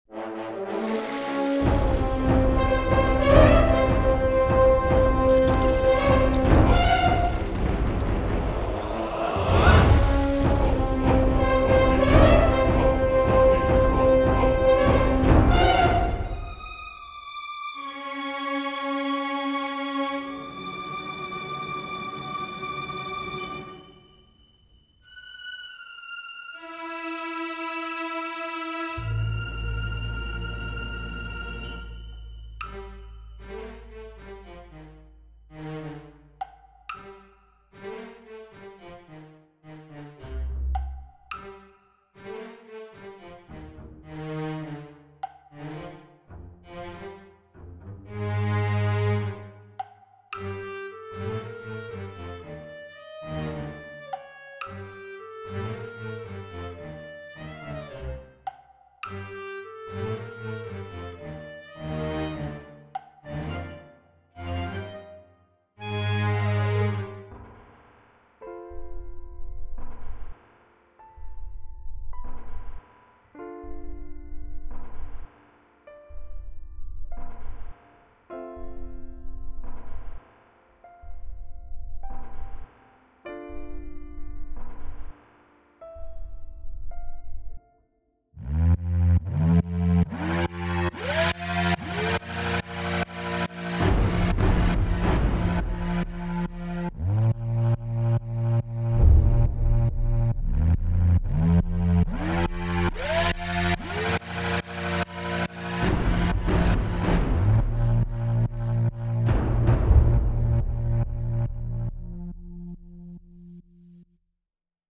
Intense & Suspense - Cinematic Examples